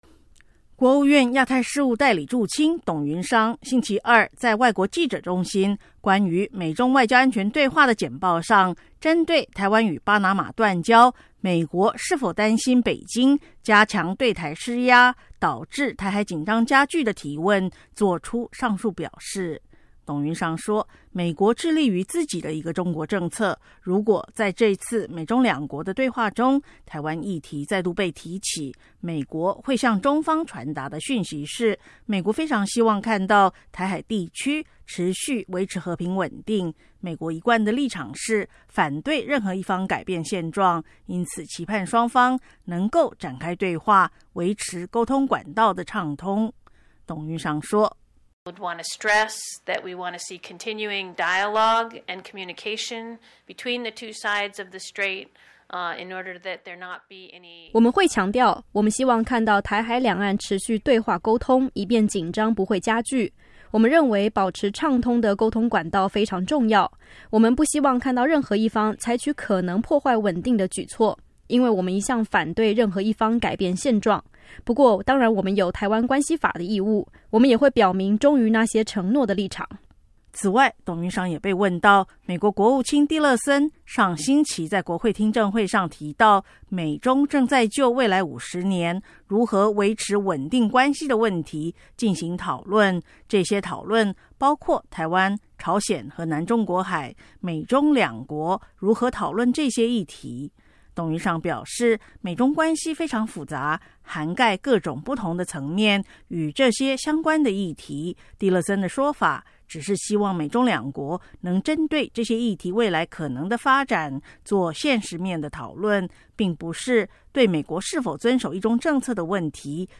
国务院亚太事务代理助卿董云裳(Susan Thornton)星期二在外国记者中心关于美中外交安全对话的简报上，针对台湾与巴拿马断交，美国是否担忧北京加强对台施压导致台海紧张加剧的提问做出上述表示。